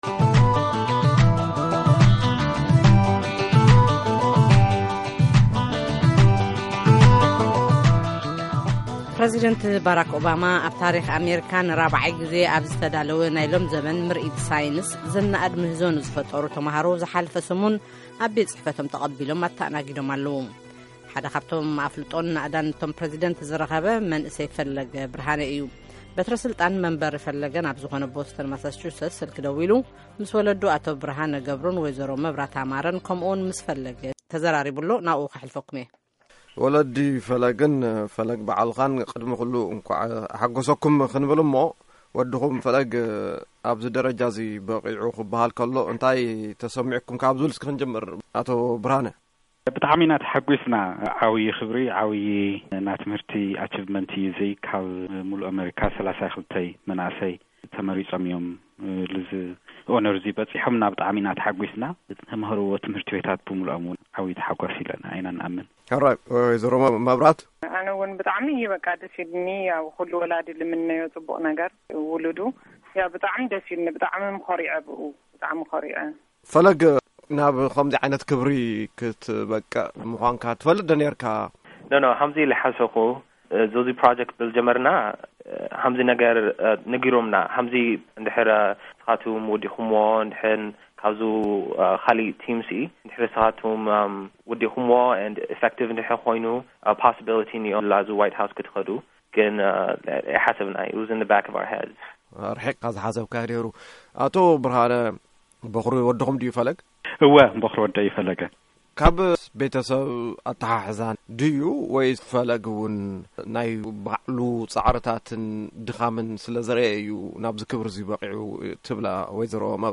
ንቀዳማይ ክፋል‘ቲ ቃለ-ምልልስ ተኸታተሉ